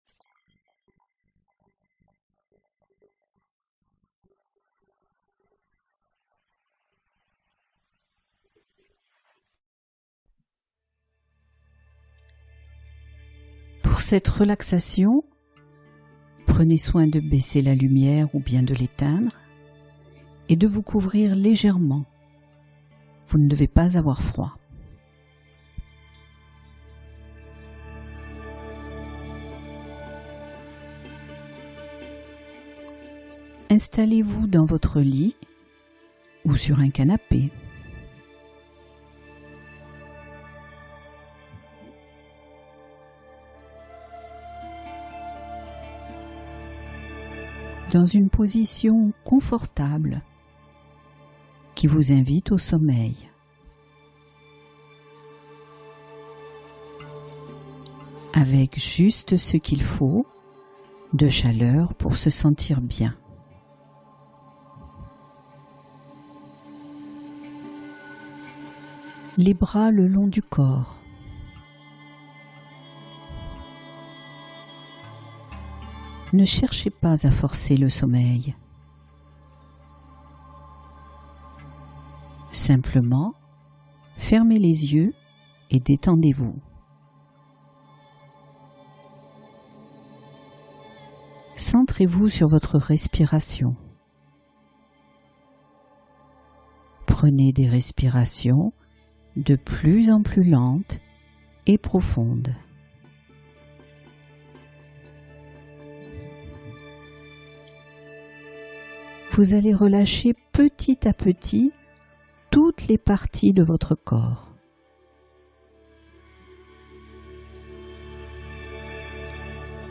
Trouvez le sommeil ce soir grâce à cette relaxation guidée ultra-efficace